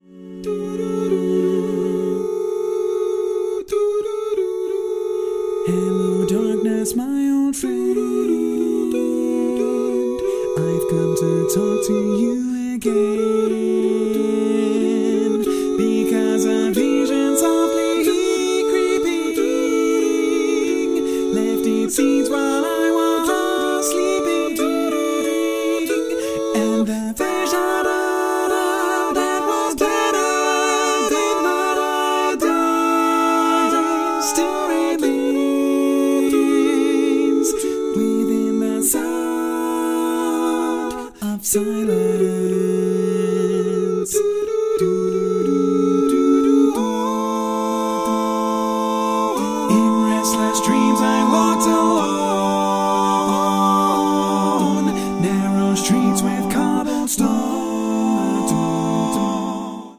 Female
(6 part chorus + solo quartet)